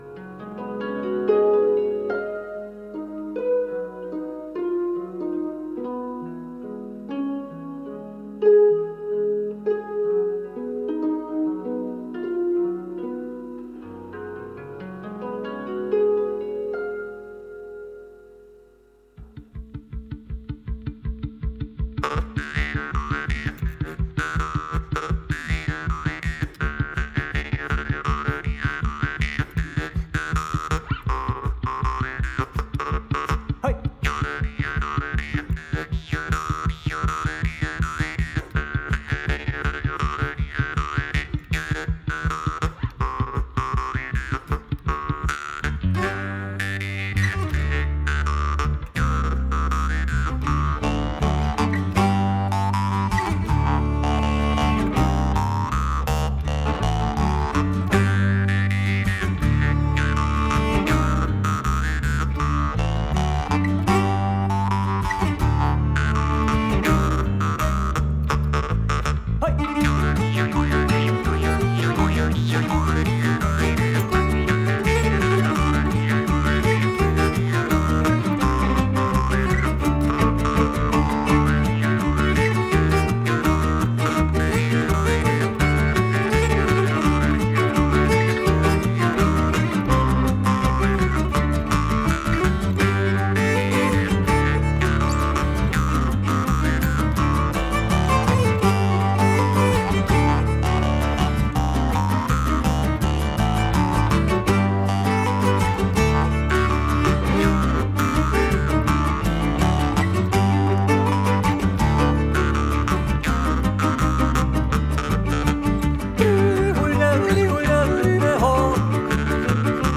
doedelzak, klarinet, mondharp en jodel
gitaar, zang
saxofoon, bas, piano